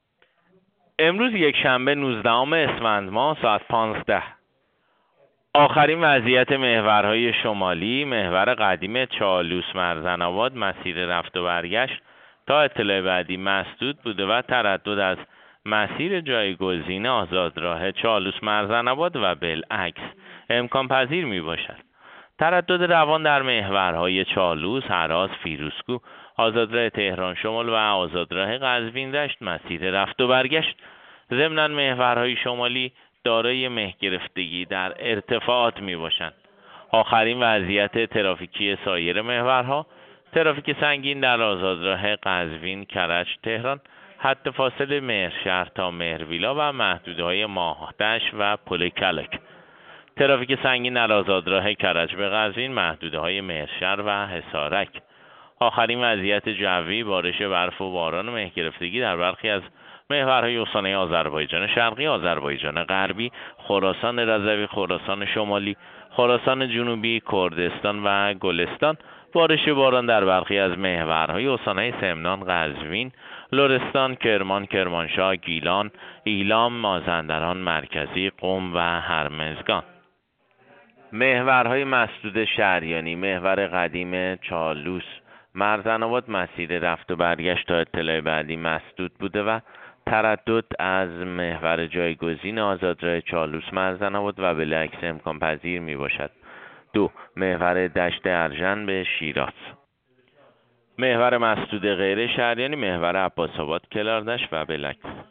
گزارش رادیو اینترنتی از آخرین وضعیت ترافیکی جاده‌ها ساعت ۱۵ نوزدهم اسفند؛